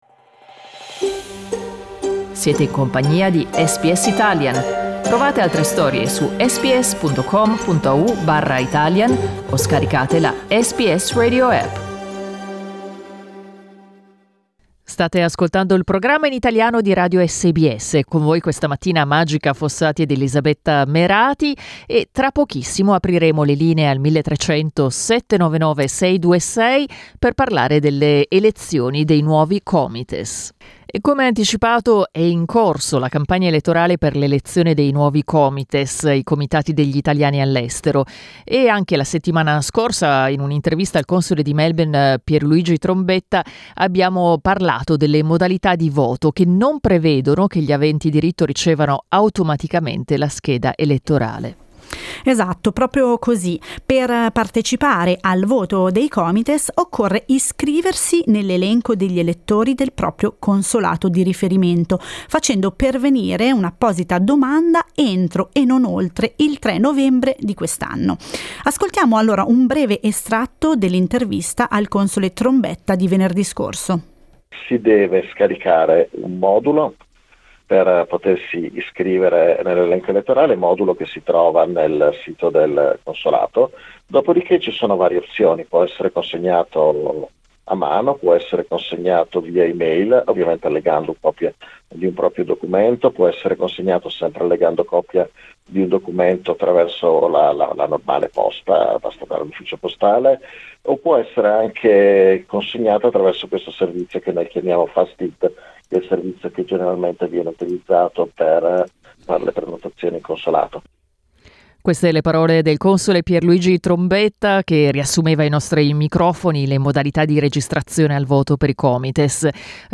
Al via in tutto il mondo la campagna elettorale per l'elezione dei COMITES, Comitati degli Italiani all’estero. In questo primo dibattito abbiamo parlato con alcuni candidati australiani.